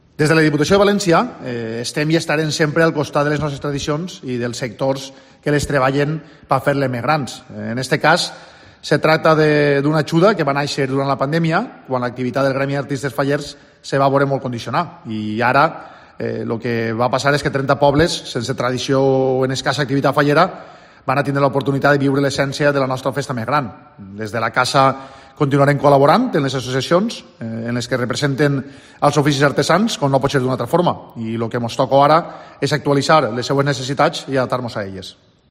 Vicent Mompó, presidente de la Diputación de Valencia, explica en COPE el objetivo de las ayudas